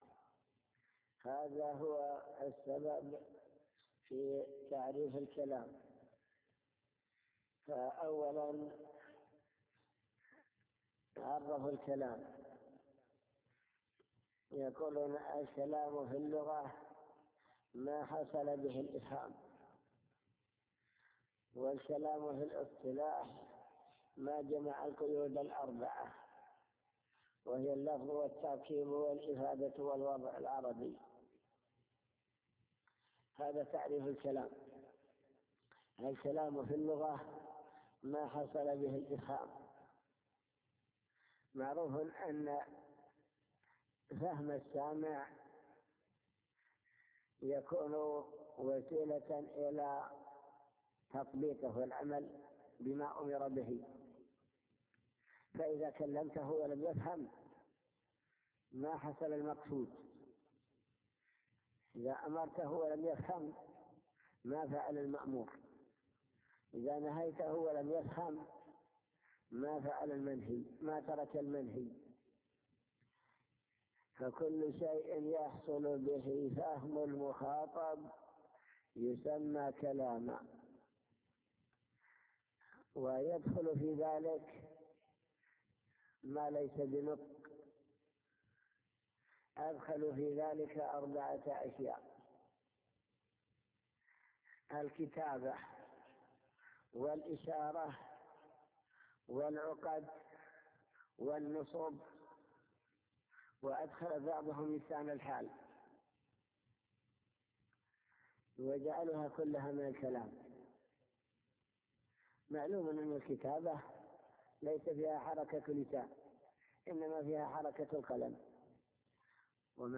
المكتبة الصوتية  تسجيلات - كتب  شرح كتاب الآجرومية الكلام في اللغة والاصطلاح